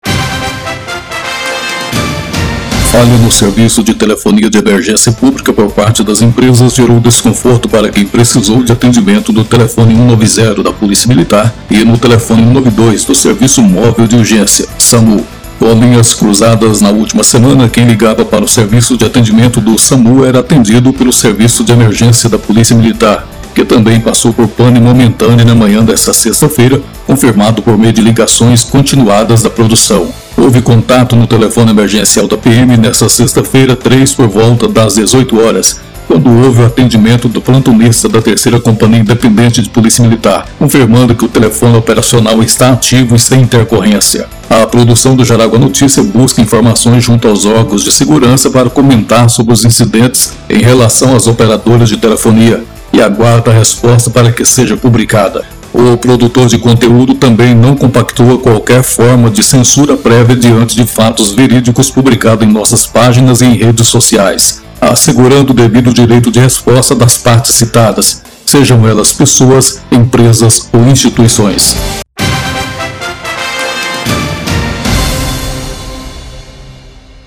VINHETA-SAMU.mp3